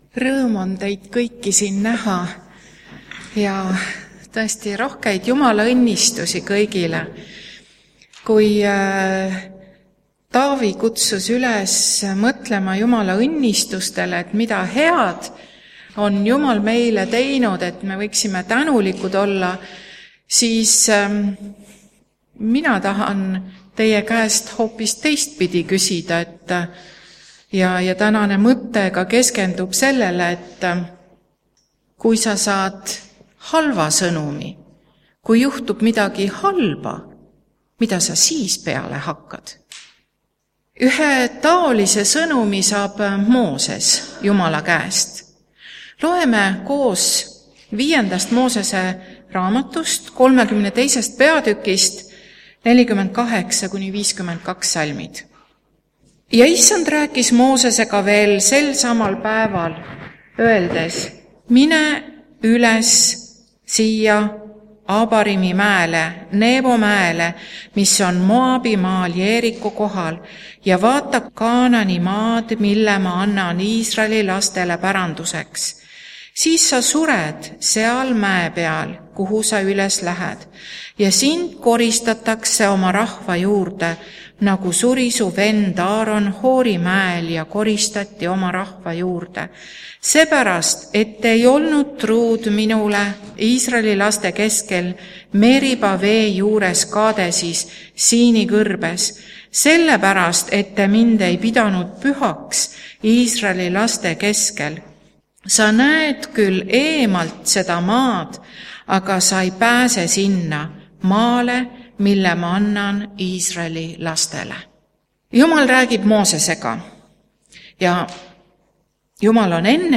Koos laulame ka laulu "Ühel päeval" Ühel päeval läheme, ühel päeval läheme, ühel päeval üle jõe.